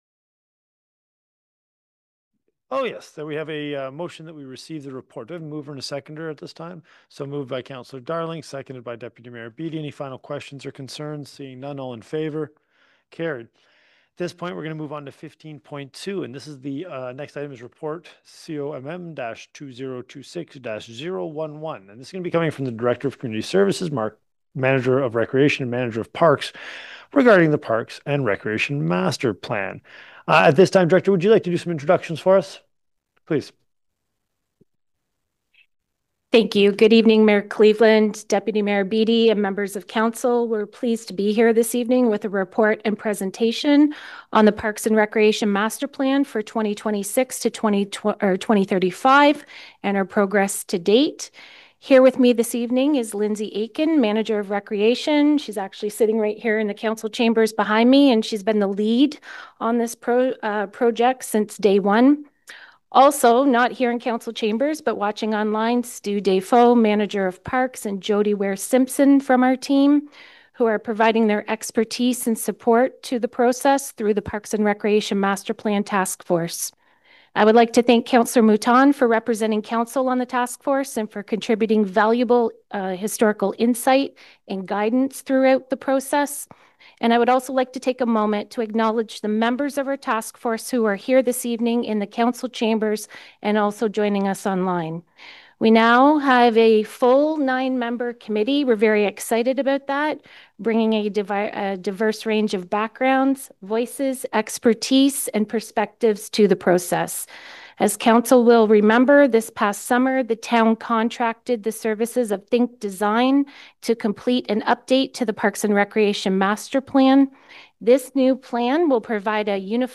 Cobourg’s parks and recreation facilities are aging, there are parking issues, and a need for more youth-focused programs, Cobourg council heard at the regular council meeting earlier this week.